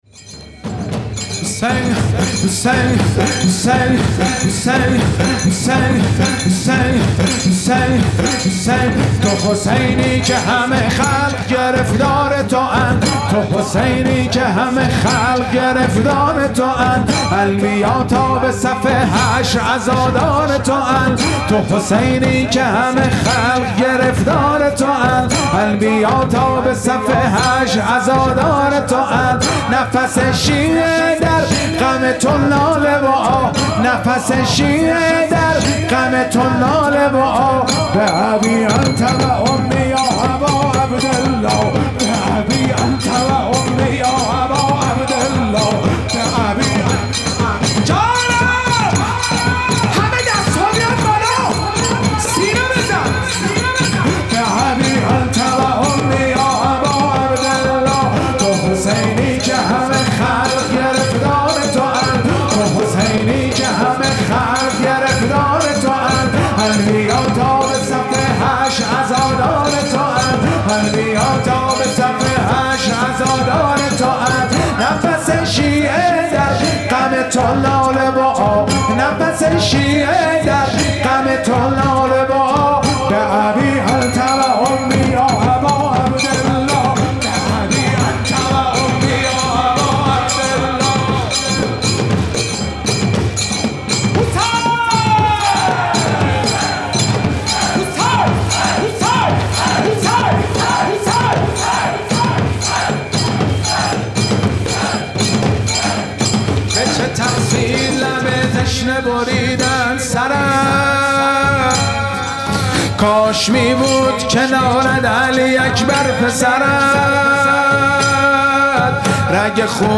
مداحی
با رعایت پروتکل های بهداشتی در پادگان ۰۶ ارتش جمهوری اسلامی ایران برگزار می شود.